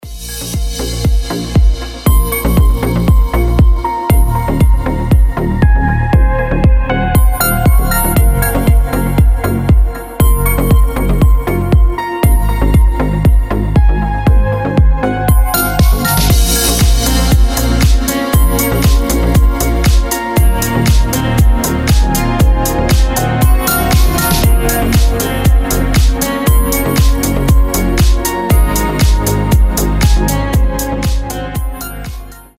• Качество: 320, Stereo
deep house
без слов
красивая мелодия
колокольчики
звонкие